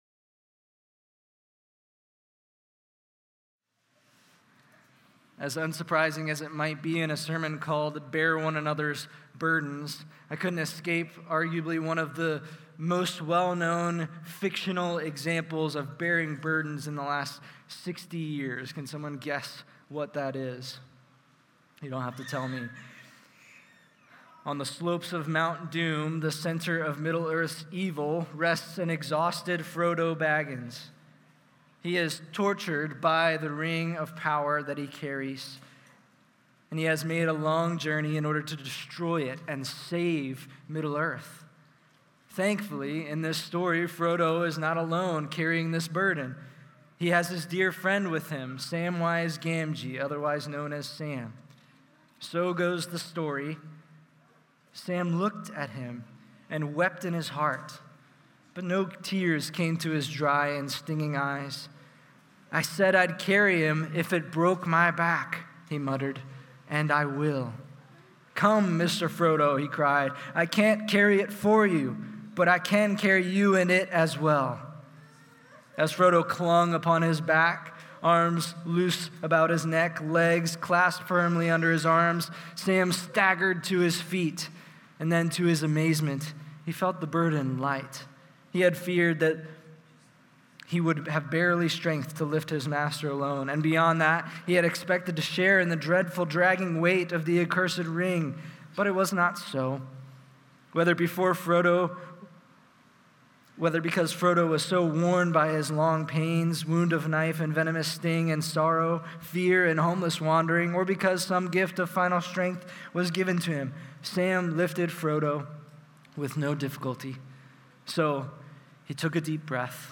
Messages given at the Sunday Morning Celebration Gathering of Sovereign Grace Church Dayton